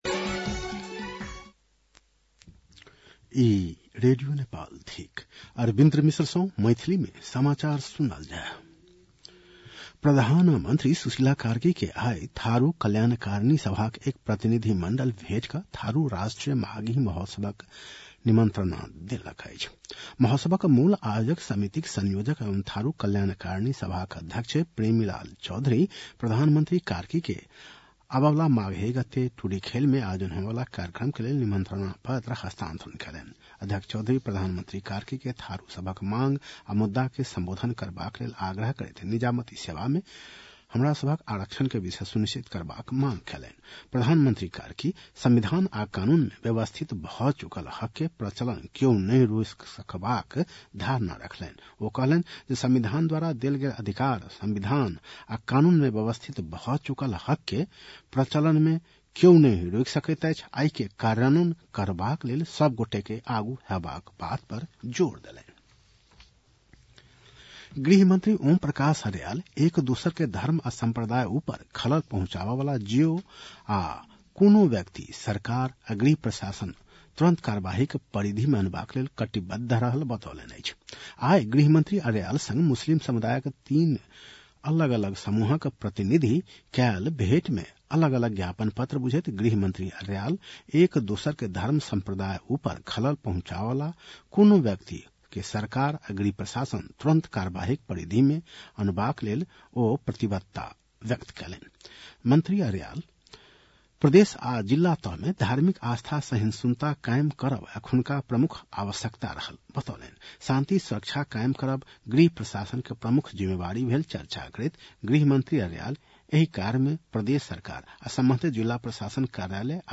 मैथिली भाषामा समाचार : २० पुष , २०८२